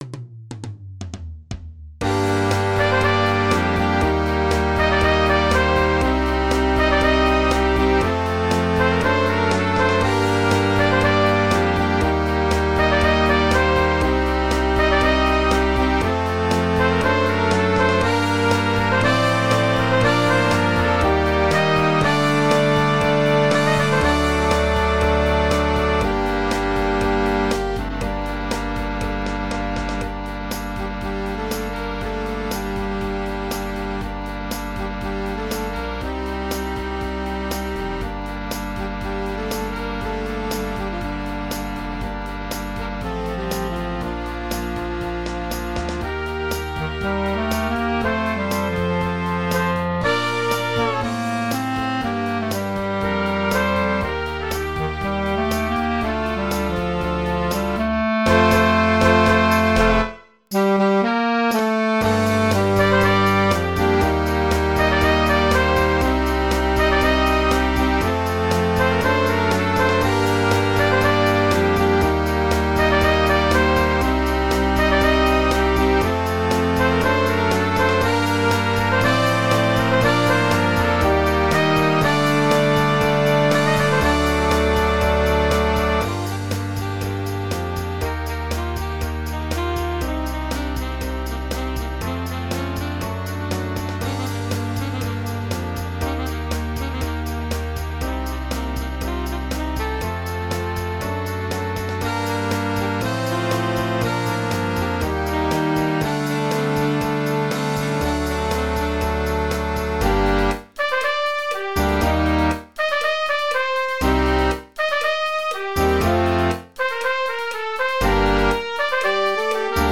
Genre: Musique de film